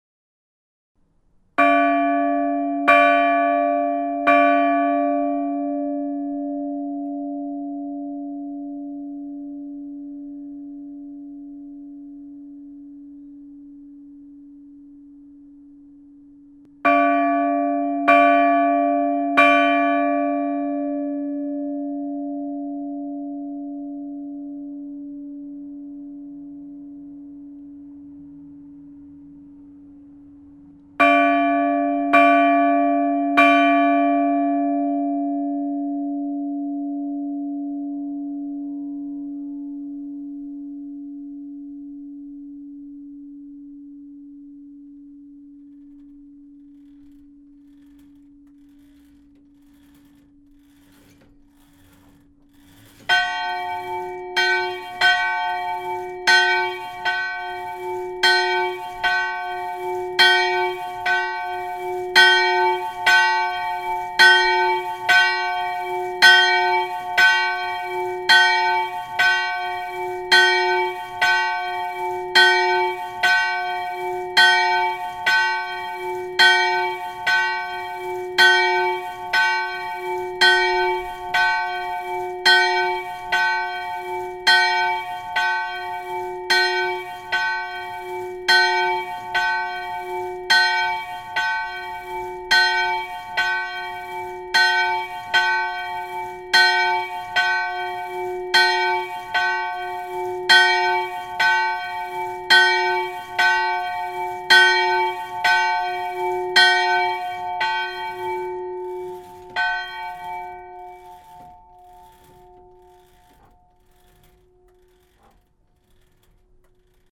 La chapelle de Beaurieux comporte deux cloches.
L'enregistrement n'est pas parfait. En effet, j'ai sonné à la main, et uniquement d'un côté. Il en ressort qu'un côté est plus fort que l'autre.